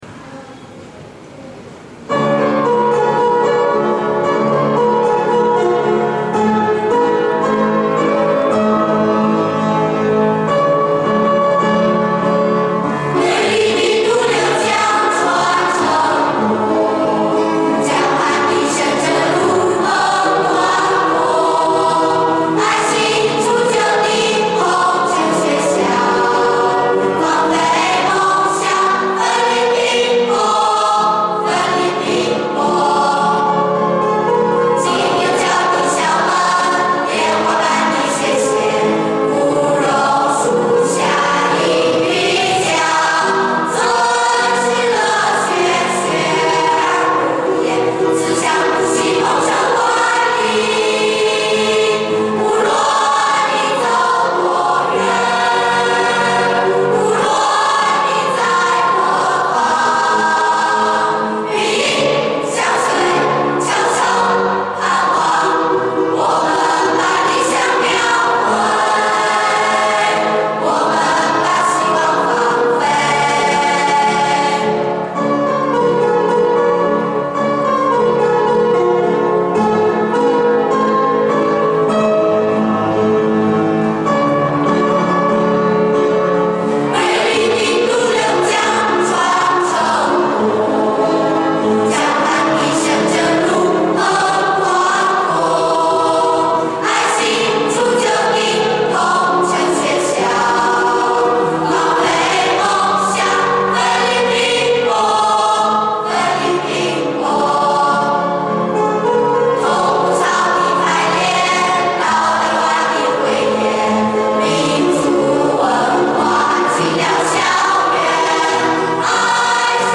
校歌 让我们把希望放飞.mp3